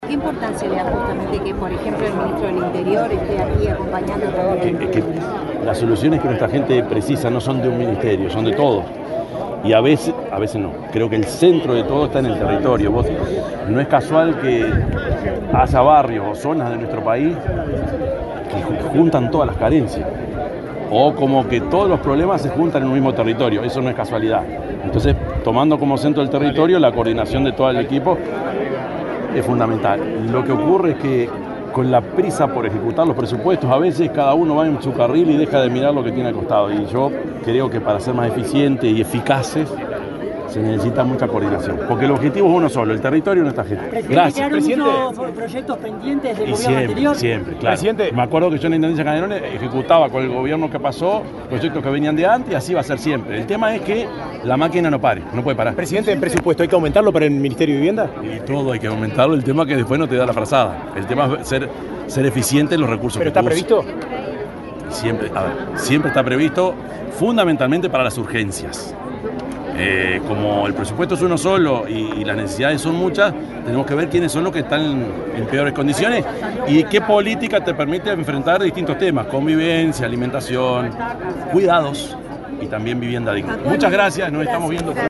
Declaraciones del presidente de la República, Yamandú Orsi
El presidente de la República, profesor Yamandú Orsi, dialogó brevemente con la prensa, luego de asistir al acto de asunción de las autoridades del